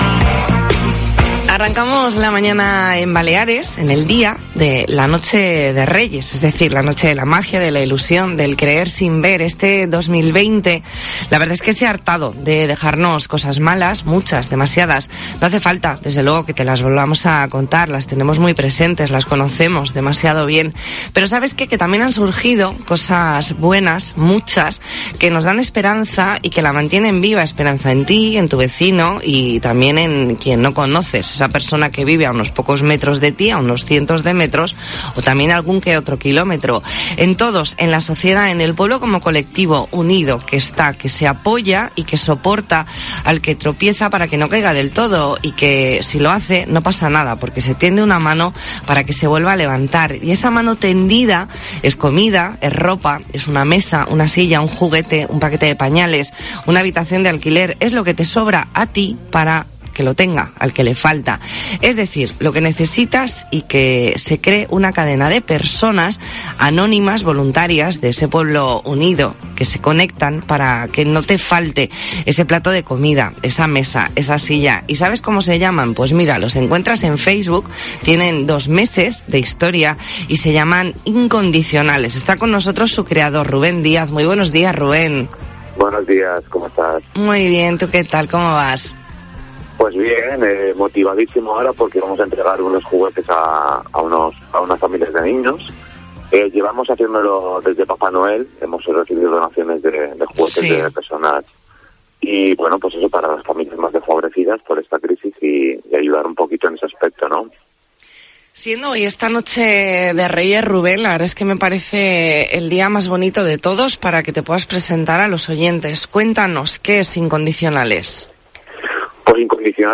Entrevista en La Mañana en COPE Más Mallorca, martes 5 de enero de 2021.